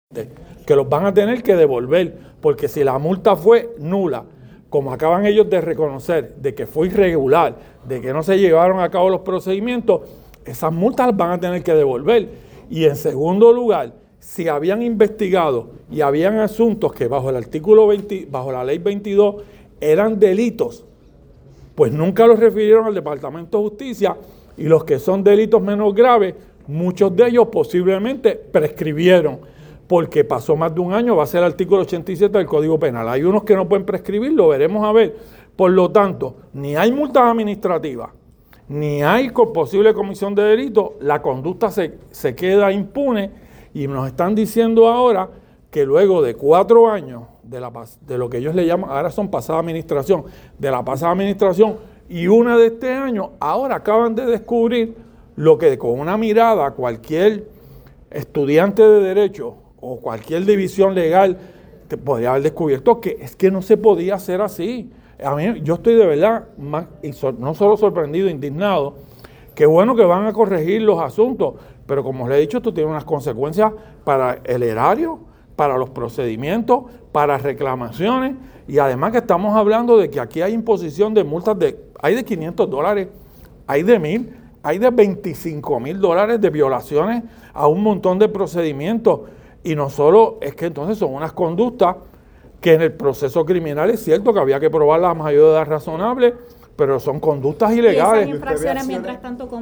Hoy se llevó a cabo la Vista Pública de la Comisión de Transportación e Infraestructura, presidida por el representante José Hernández Concepción, sobre la Resolución de la Cámara 352 para realizar una investigación detallada en torno a la implantación del Artículo 12 de la Ley 22-2000, según enmendada, que establece que todo vehículo de motor que transite por las vías públicas debe ser sometido a inspecciones mecánicas periódicas, con particular énfasis en el requisito de que todos los centros de inspección de vehículos deban adoptar un nuevo sistema y equipo para llevar a cabo tales inspecciones; y para otros fines relacionados, a esos efectos el representante Denis Márquez, manifestó que devolver el dinero cobrado por las multas.